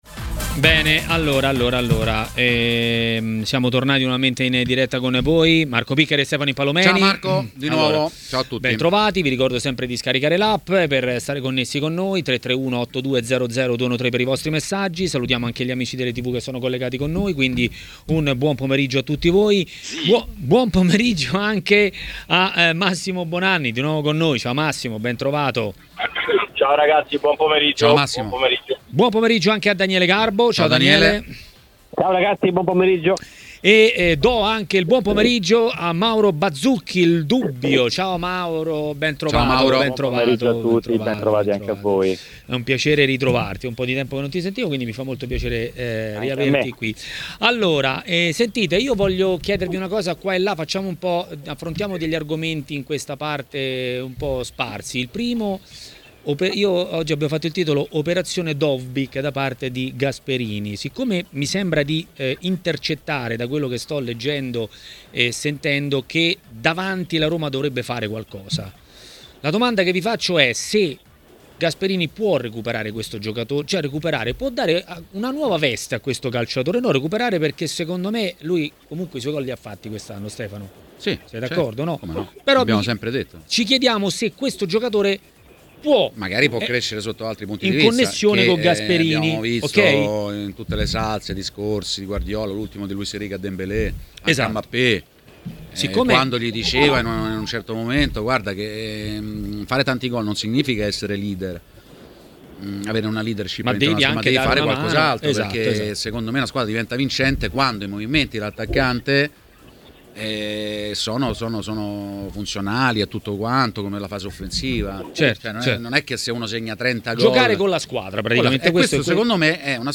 ha parlato dei temi del giorno a TMW Radio, durante Maracanà.